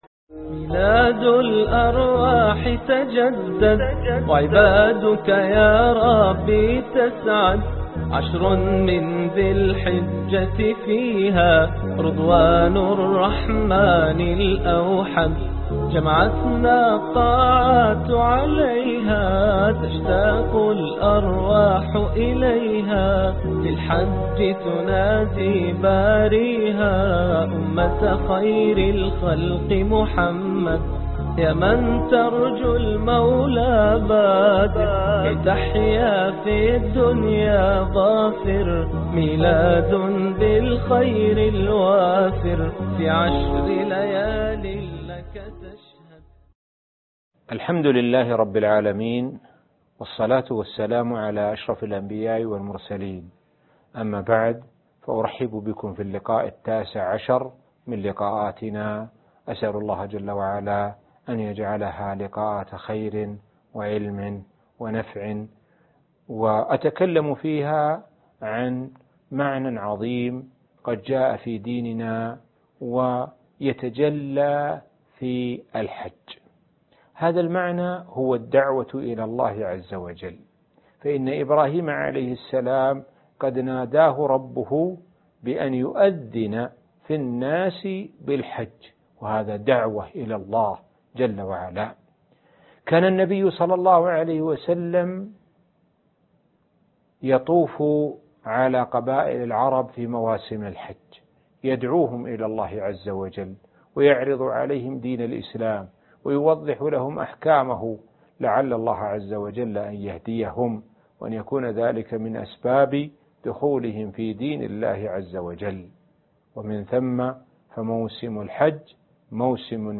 الدرس التاسع عشر ( ميلاد جديد - الحج ) - الشيخ سعد بن ناصر الشثري